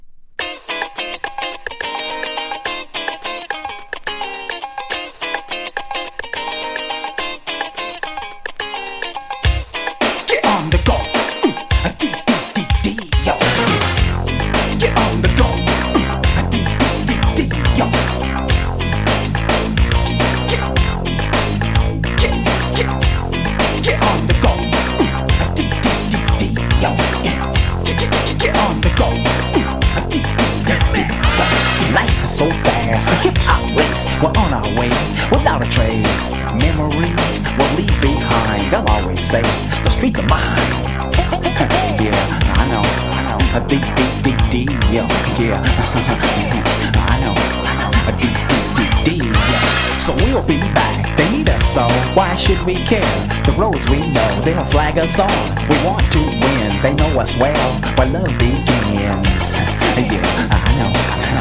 モダン・ディスコ+ラッピン